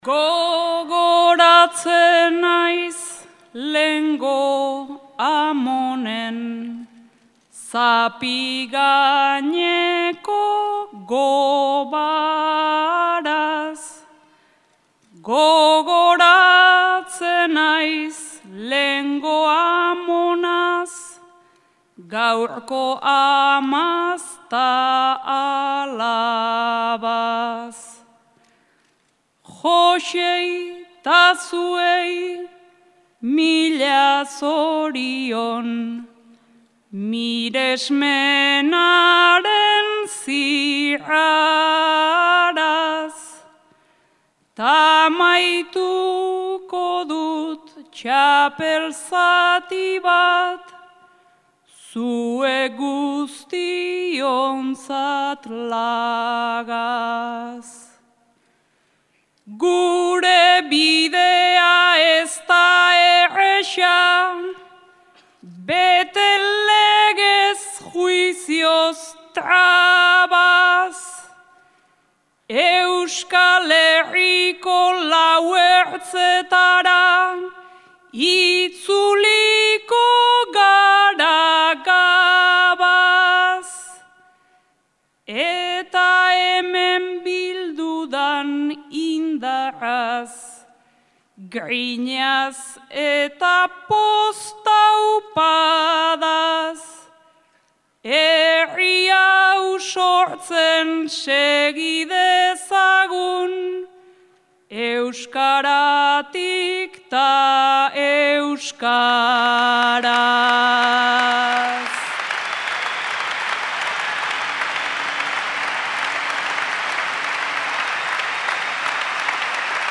Bena Barakaldoko (Bizkaia) BEC gela erraldoi hartara hüilantü den orok ikusi dü Eüskal Herriko Bertsolari Txapelketa Nagusiko finala batek zonbat indar badüan. 14 500 ikuslek bat egin züen igantean bertsoak sortzen düan emozionean.
Eta txapeldün berriaren agurra :